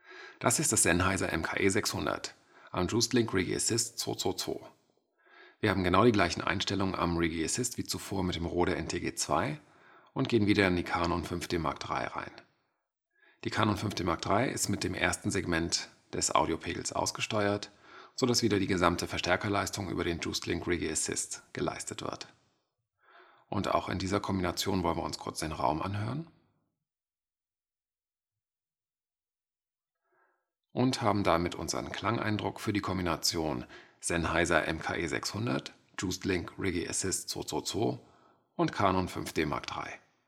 Und hier die Audio-Files mit einem leichten EQ und stärkerer Rauschunterdrückung:
Sennheiser MKE600 via juicedLink Riggy Assist 222 an Canon 5D Mark III + EQ + DeNoise